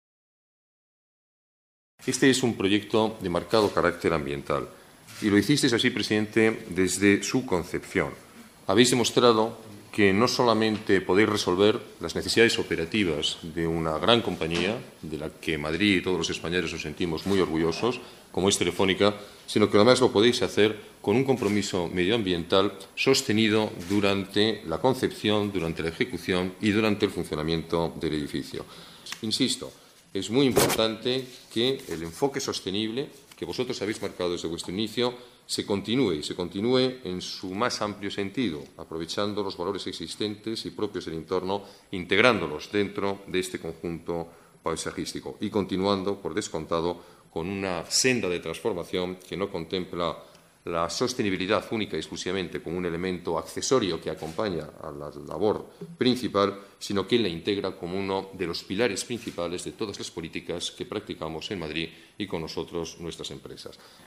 Nueva ventana:Declaraciones del alcalde de la Ciudad de Madrid, Alberto Ruiz-Gallardón: Convenio Telefónica Jardines Sostenibles